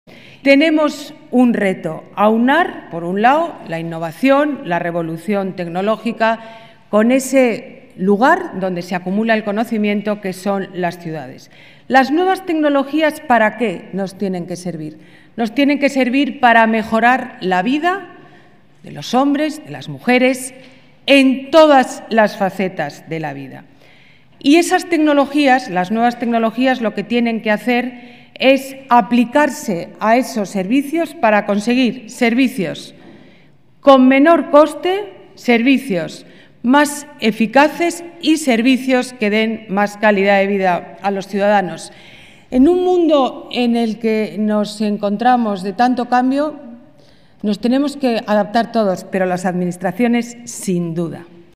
Ana Botella inaugura la jornada de Startup4cities, una iniciativa de Fundetec y la Red Española de Ciudades Inteligentes
Nueva ventana:Declaraciones de la alcaldesa: apoyo a emprendedores